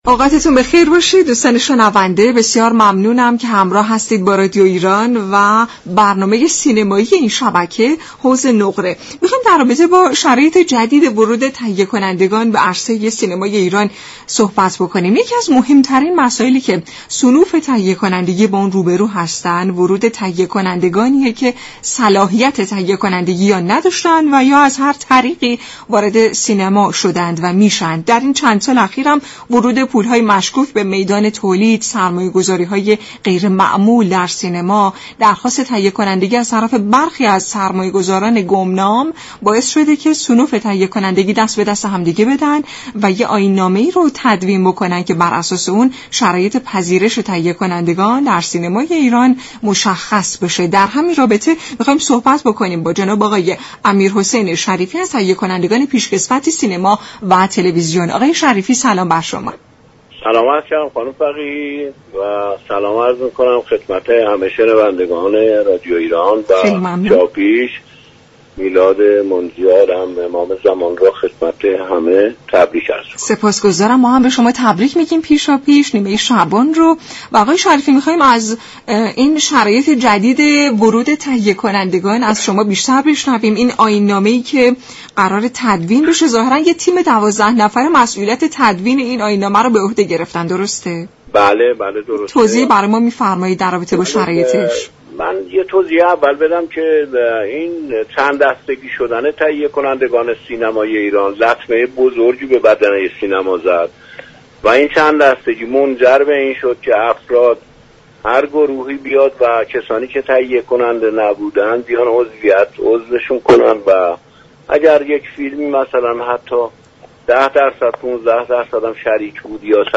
برنامه حوض نقره جمعه ها ساعت 17 از رادیو ایران پخش می شود. این گفت و گو را در ادامه باهم می شنویم.